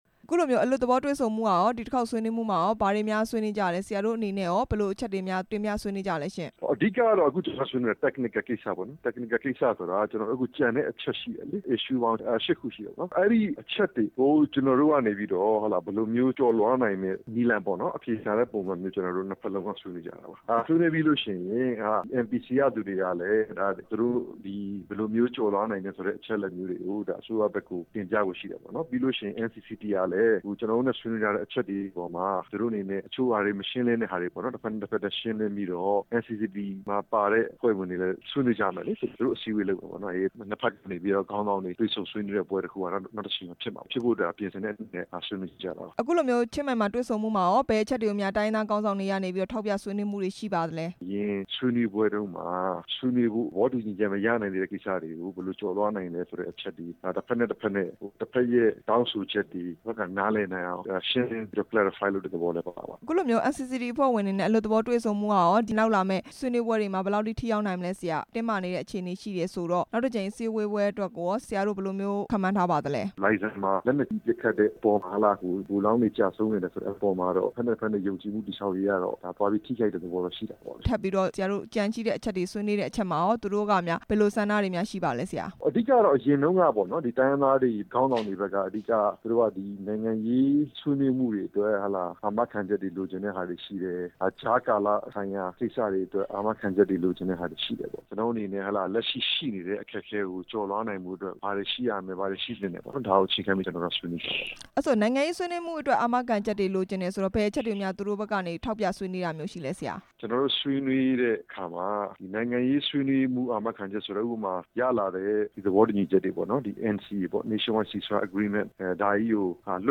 ချင်းမိုင်မြို့က အပစ်ခတ်ရပ်စဲရေး သဘောတူစာချုပ် ဆွေးနွေးပွဲ အကြောင်း မေးမြန်းချက်